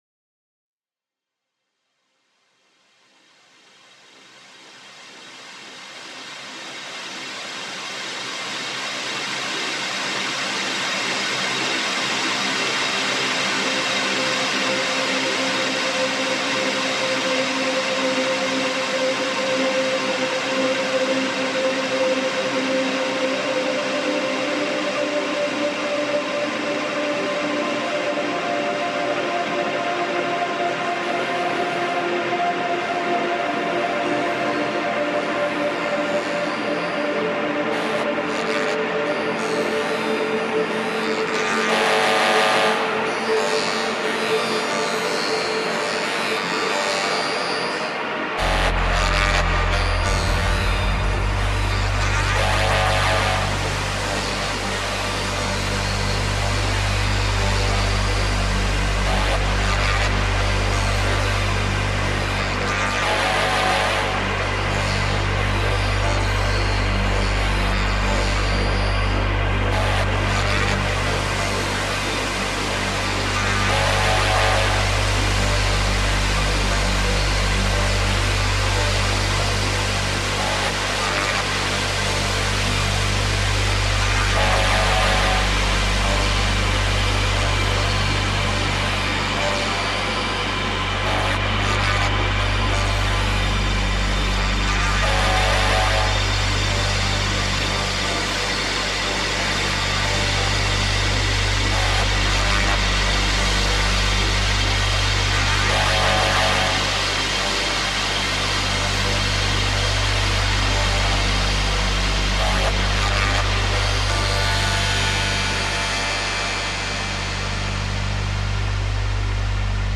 Composition